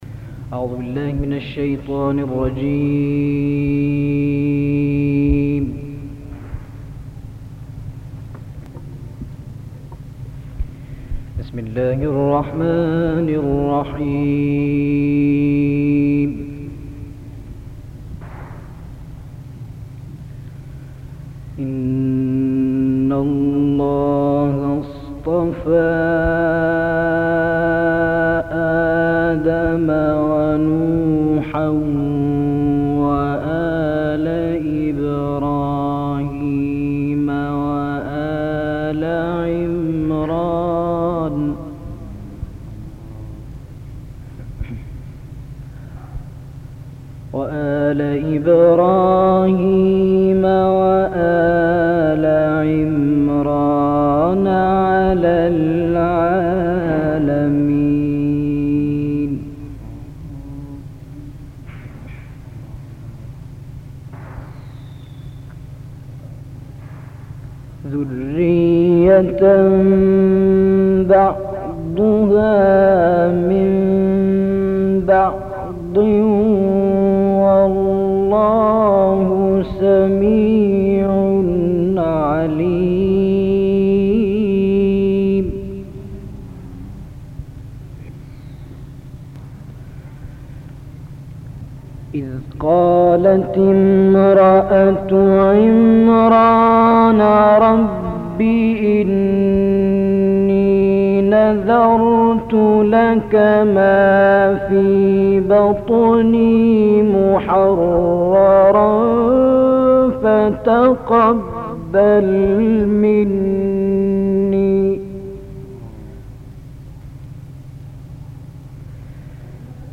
قرائت